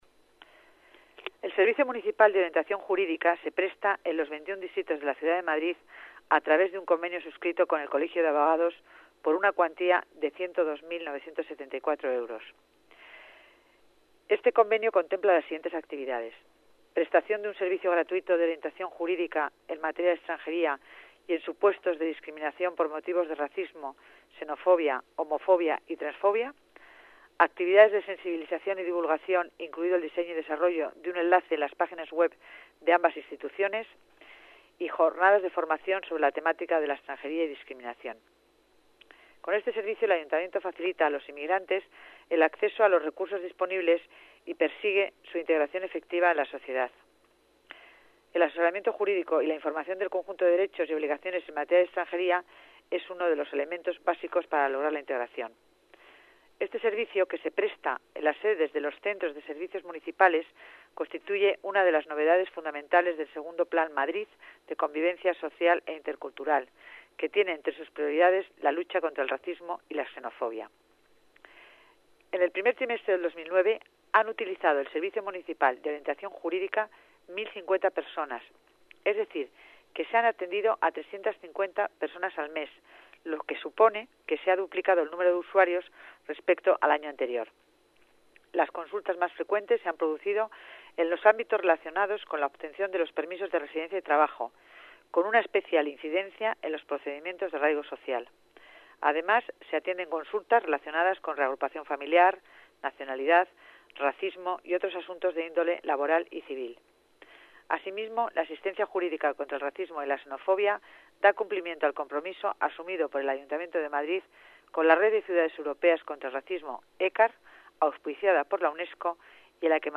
Nueva ventana:Declaraciones delegada Familia y Asuntos Sociales, Concepción Dancausa: servicio de atención jurídica